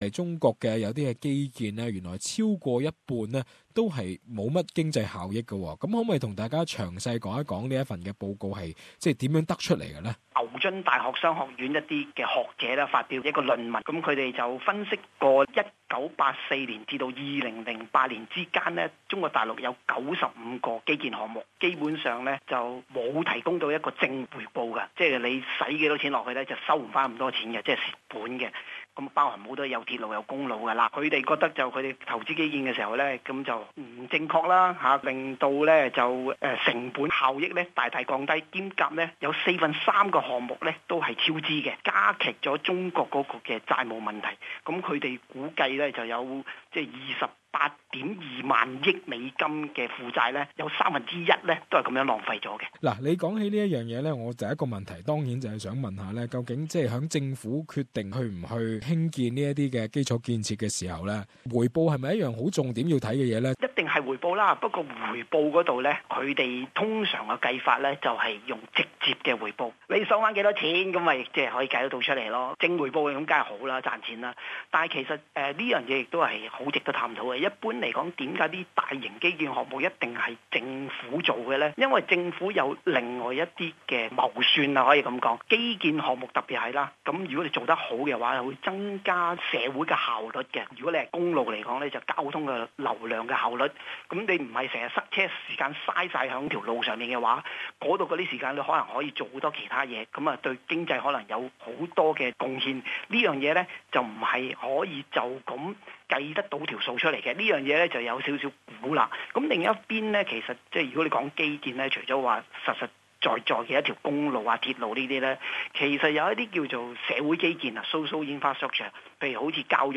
A new study by the Saïd Business School finds that low-quality infrastructure investments pose significant risks to the Chinese and the global economy. It argues that over half of the infrastructure investments in China have destroyed rather than generated economic value. In an interview